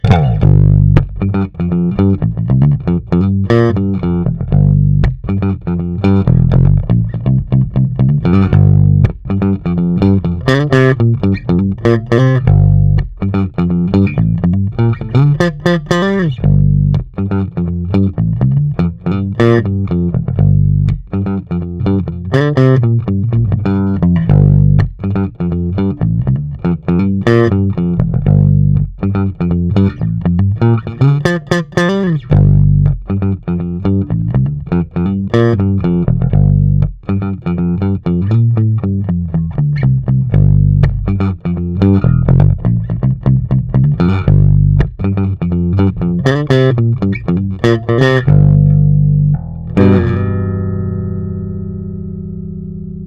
Bicentennial 1976 Bass Pickup
Bicetennial-1976-demo.mp3